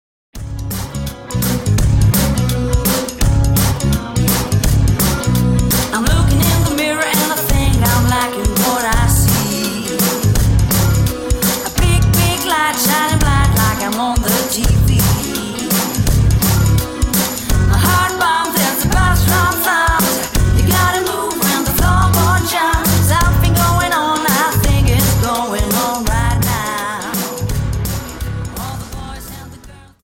Dance: Jive